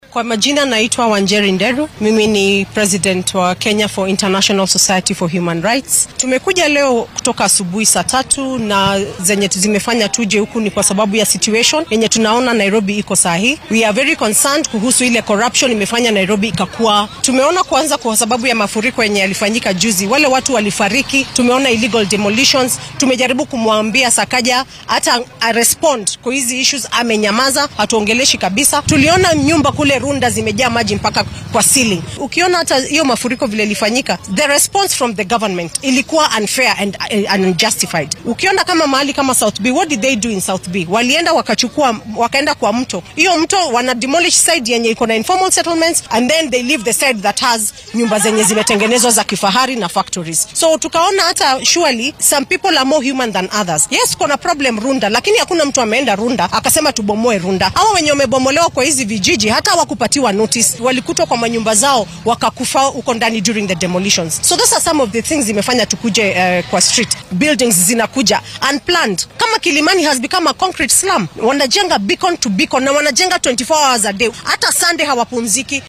Mid ka mid ah mas’uuliyiinta ururrada rayidka ee maanta dibadbaxa sameeyay ayaa warbaahinta la wadaagtay dareenkeeda.